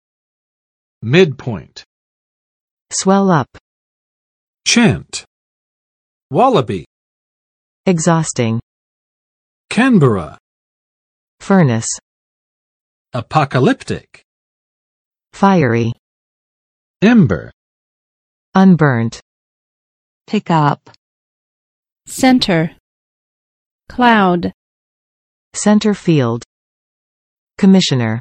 [ˋmɪd͵pɔɪnt] n. 中点